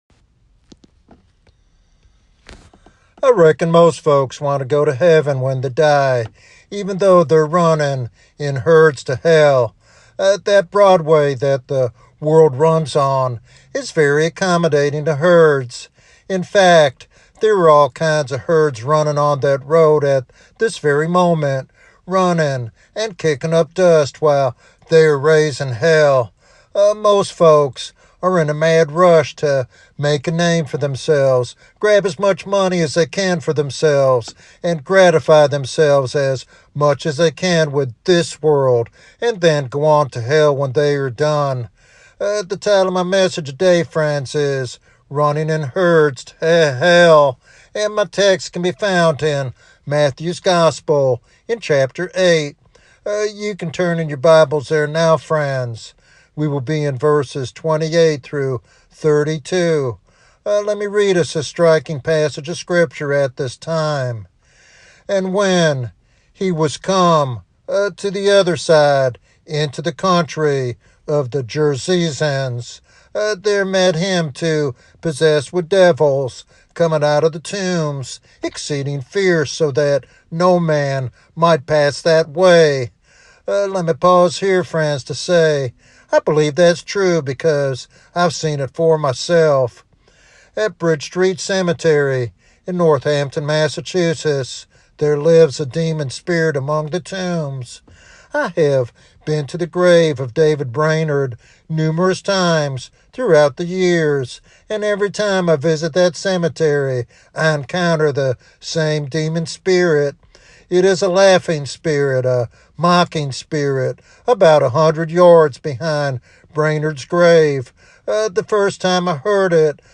This sermon calls for genuine faith and regeneration through Jesus Christ.